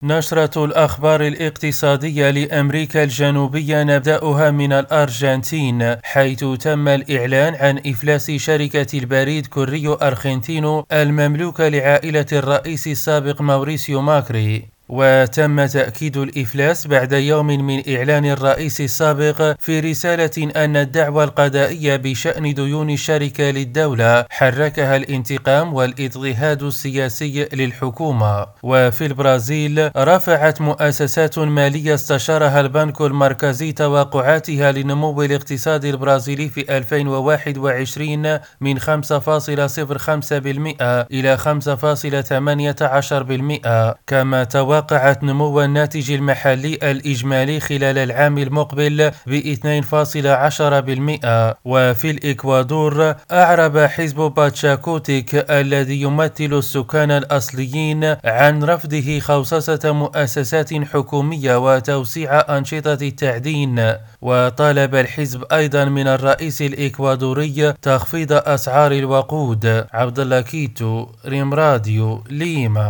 النشرة الاقتصادية لأمريكا الجنوبية